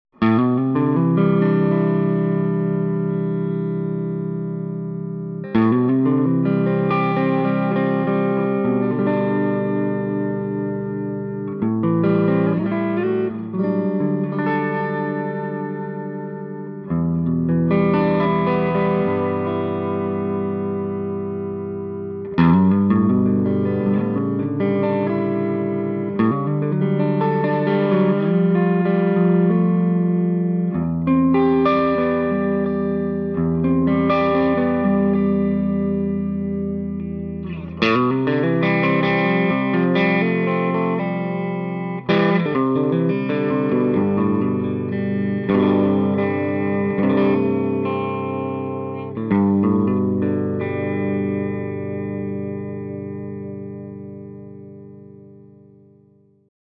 With the EL-34s, the SST-30 takes on a more British tone without losing the richness of the midrange.